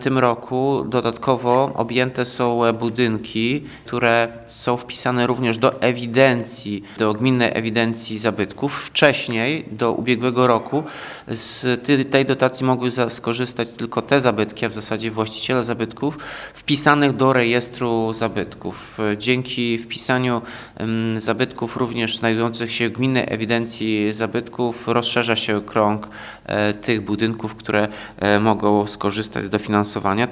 – W tym roku zwiększyła się liczba obiektów, których remont może być dofinansowany – mówi prezydent Ełku Tomasz Andrukiewicz.